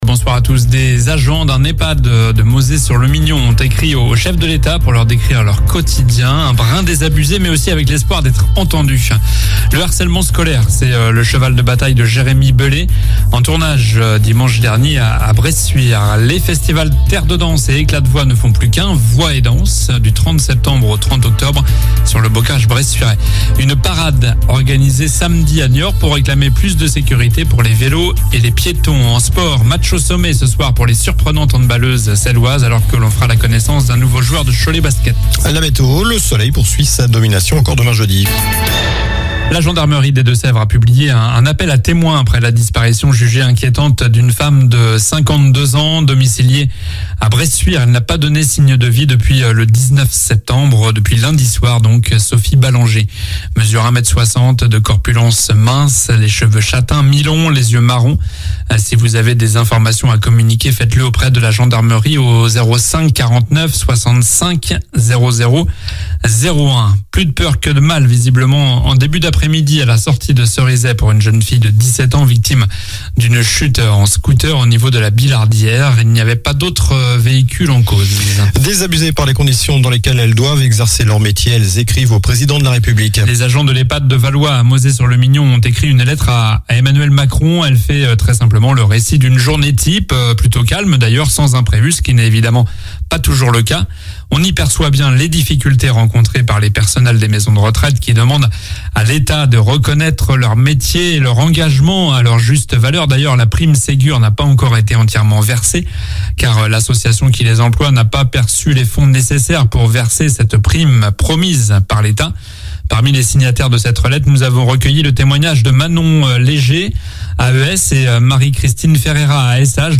Journal du mercredi 21 septembre (soir)